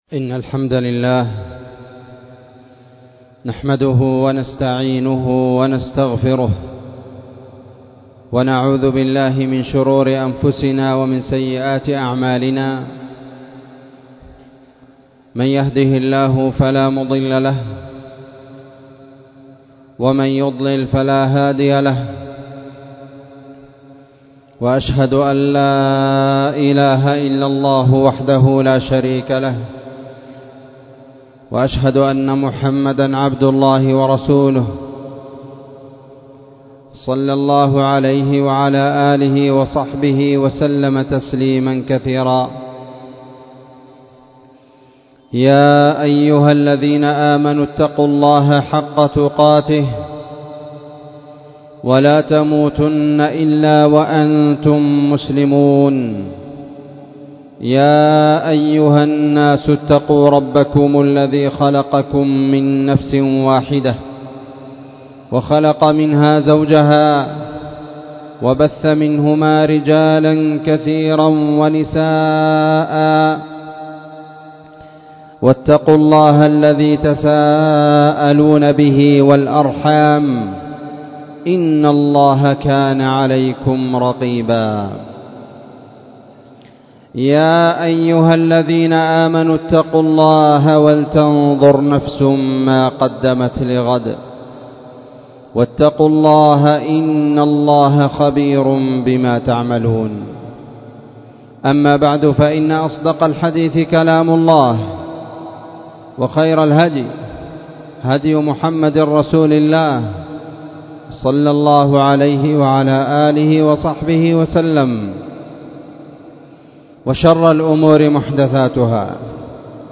خطبة قيمة
والتي كانت في مسجد المجاهد- تعز